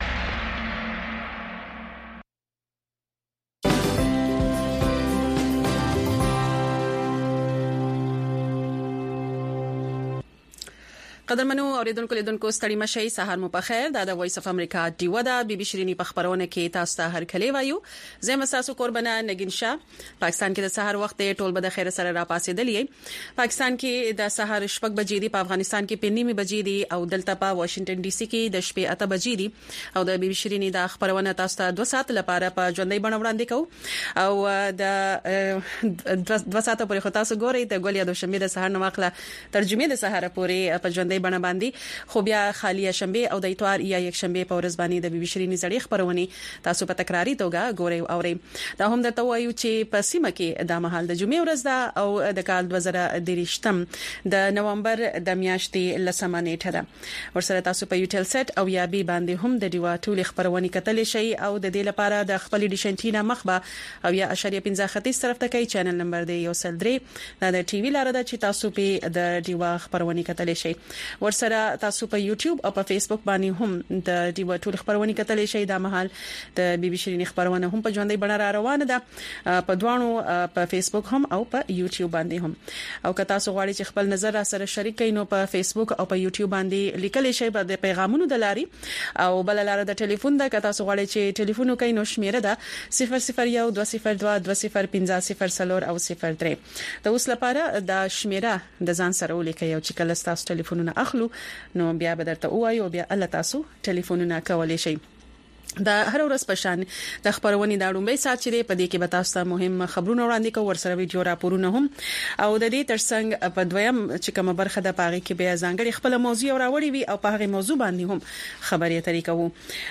د وی او اې ډيوه راډيو سهرنې خبرونه چالان کړئ اؤ د ورځې د مهمو تازه خبرونو سرليکونه واورئ.